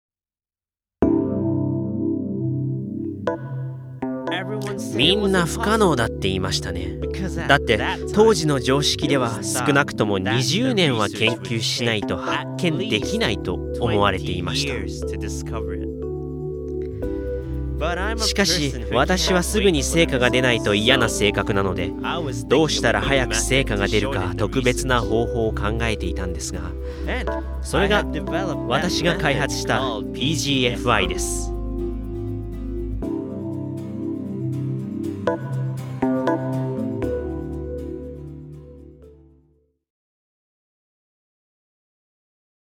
外国人が語っている原音を生かしながら同時に翻訳された日本語を合わせて表現する手法のことです。
以下は、「ボイスオーバー」の制作例です。（参考）